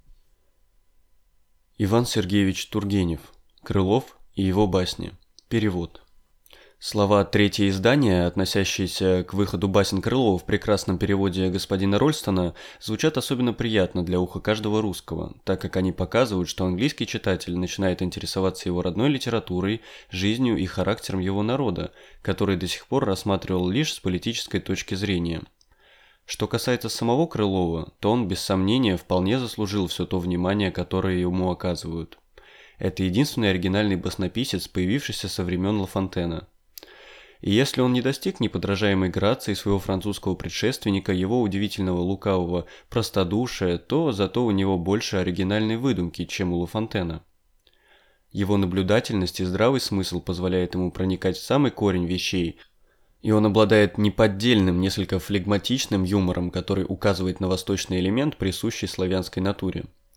Аудиокнига Крылов и его басни | Библиотека аудиокниг